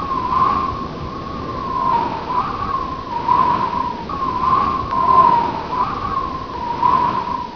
Sons da natureza 18 sons
vento2.wav